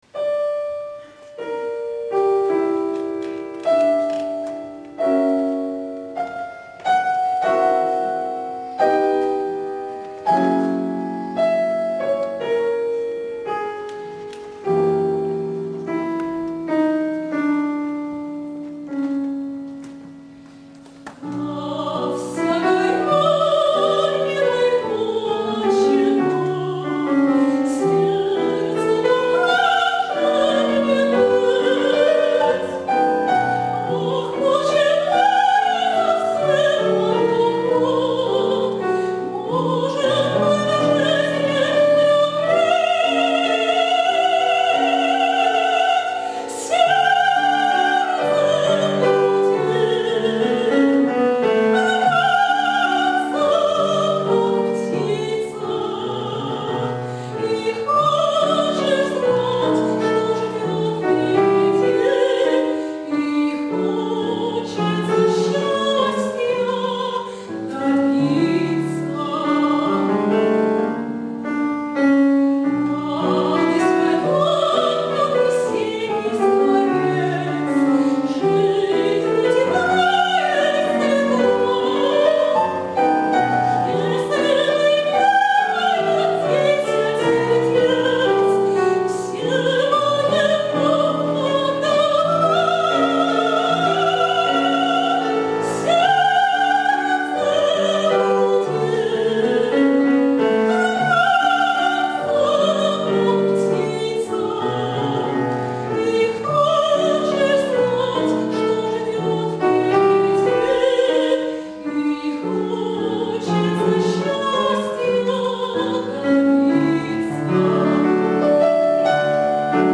драматическое сопрано